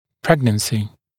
[‘pregnənsɪ][‘прэгнэнси]беременность